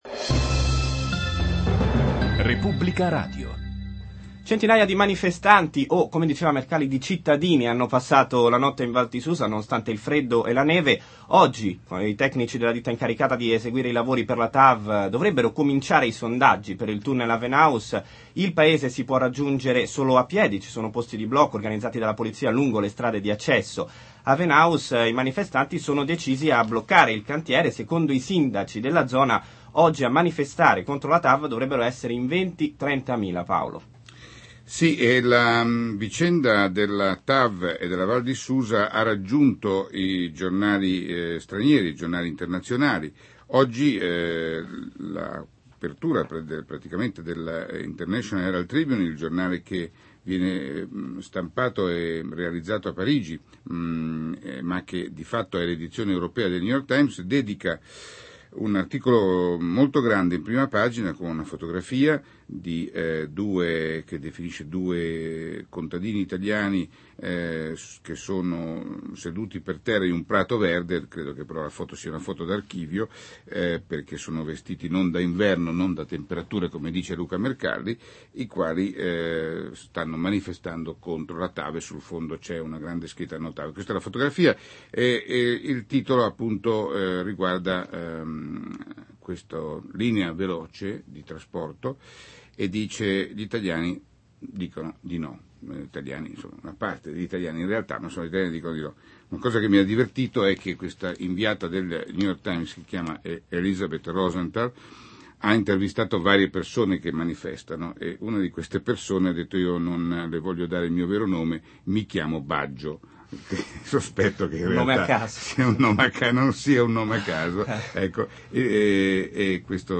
6/12/2005: Scarica l'mp3 con le testimonianze dei giornalisti, dei cittadini, dei sindaci e di Mercedes Bresso, presidente della Regione Piemonte (.mp3, 6 mega)